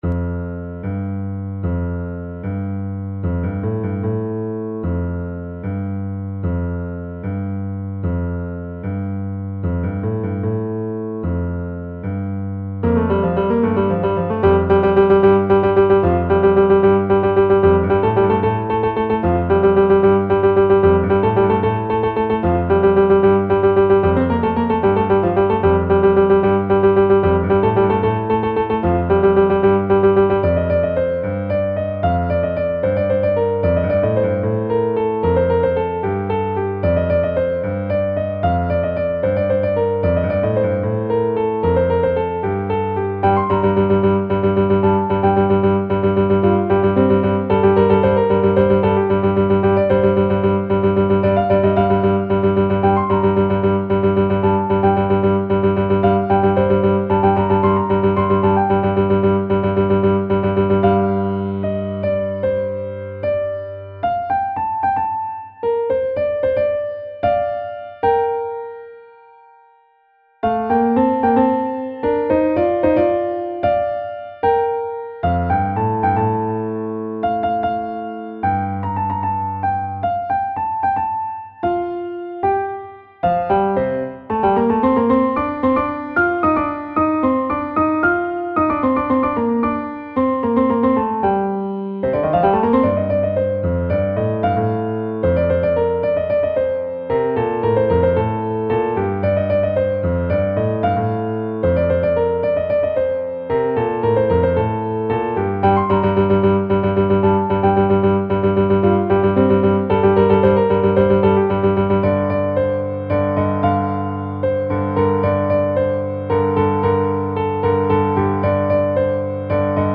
نت پیانو
موسیقی بیکلام